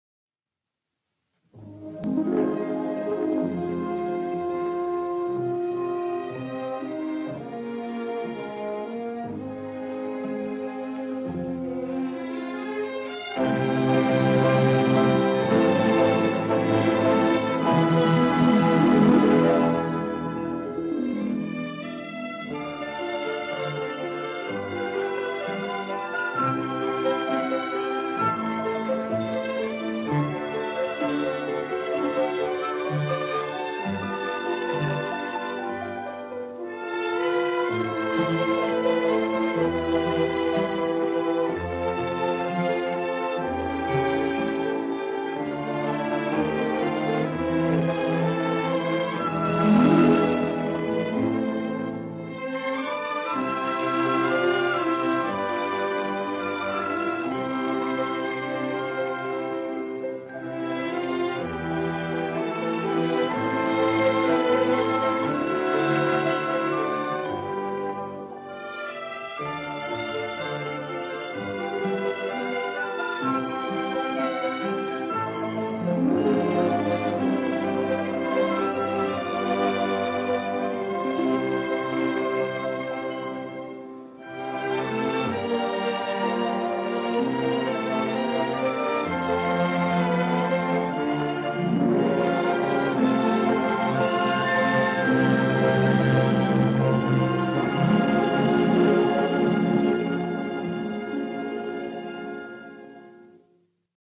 Deleitable y melancólica música naïf